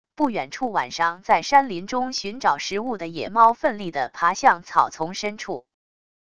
不远处晚上在山林中寻找食物的野猫奋力的爬向草丛深处wav音频